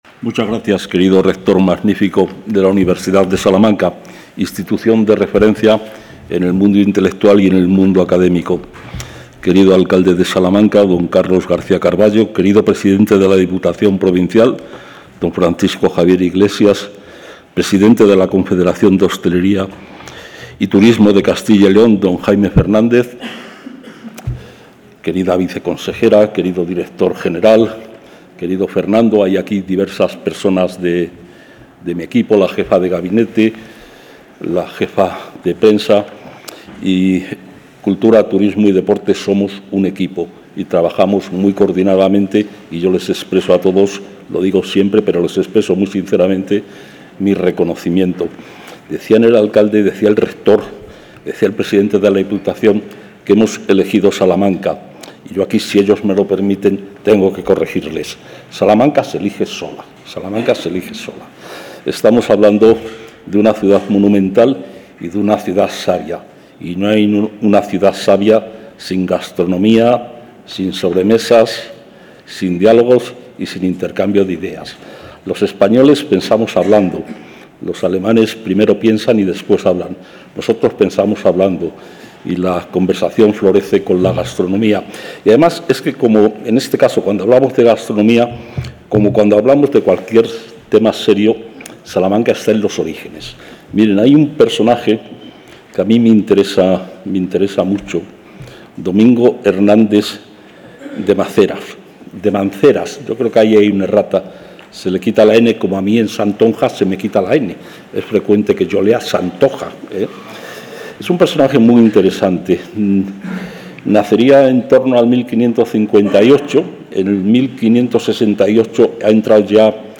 El consejero de Cultura, Turismo y Deporte, Gonzalo Santonja, ha presentado hoy, en el ‘Salón de Pinturas’ del colegio Arzobispo...
Intervención del consejero.